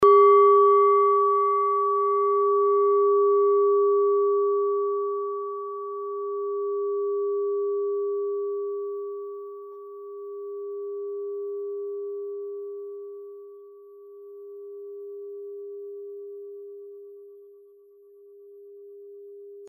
Klangschale Nepal Nr.35
(Ermittelt mit dem Filzklöppel)
Die Pi-Frequenz kann man bei 201,06 Hz hören. Sie liegt innerhalb unserer Tonleiter nahe beim "Gis".
klangschale-nepal-35.mp3